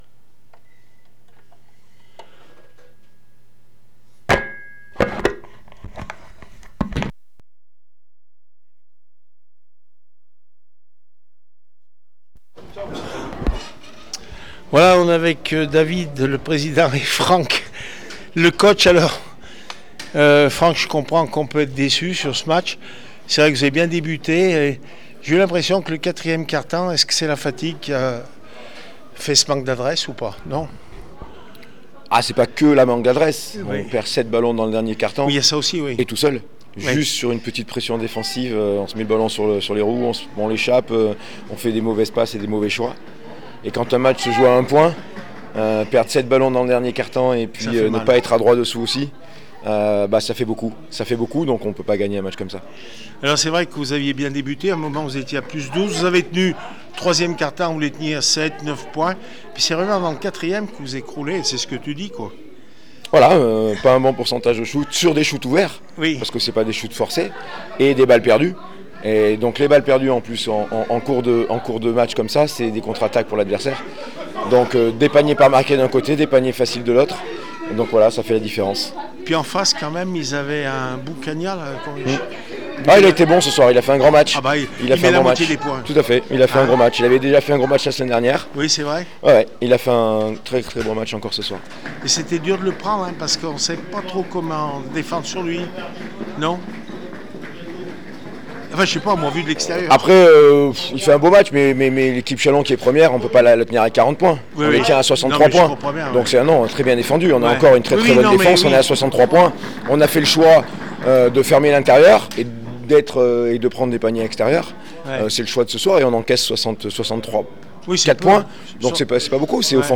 handi basket Elite les aigles du Velay 63-64 élan de chalon réaction après match